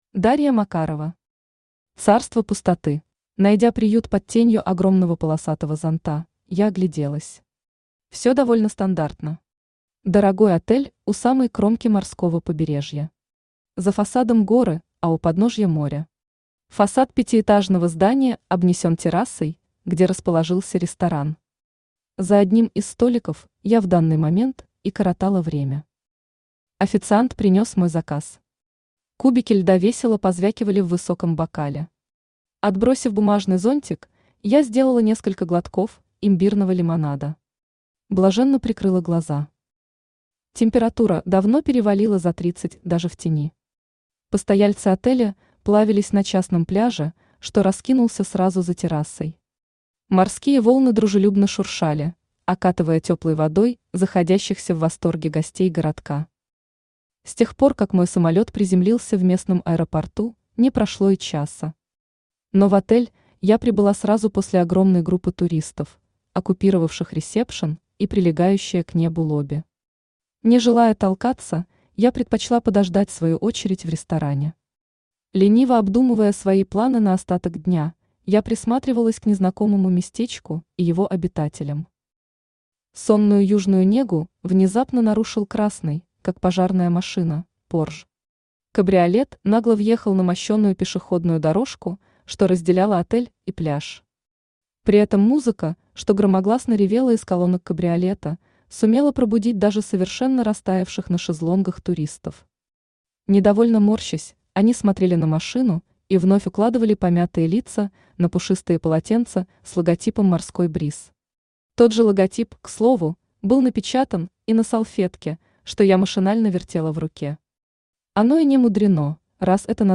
Аудиокнига Царство пустоты | Библиотека аудиокниг
Aудиокнига Царство пустоты Автор Дарья Макарова Читает аудиокнигу Авточтец ЛитРес.